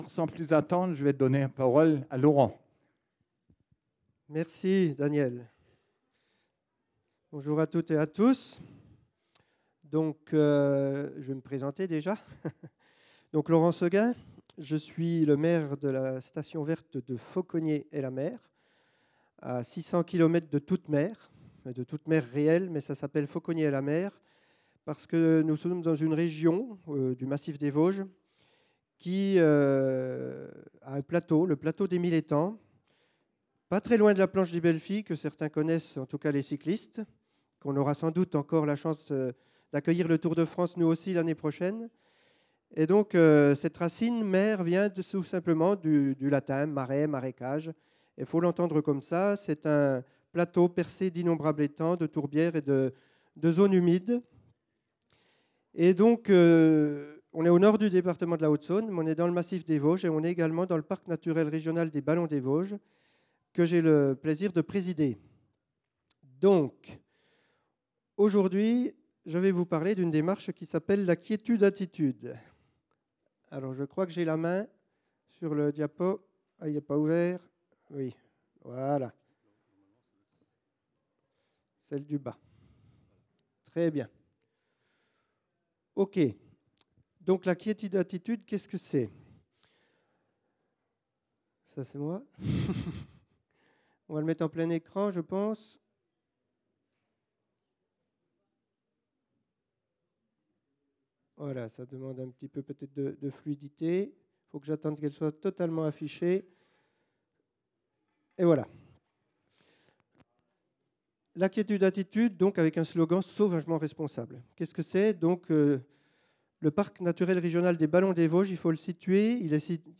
Congrès national des Stations Vertes, Station Verte : Station Verte : loisirs, tourisme et vacances Nature dans 600 destinations